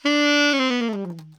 Index of /90_sSampleCDs/Giga Samples Collection/Sax/ALTO DOUBLE
ALTOLNGFLD 3.wav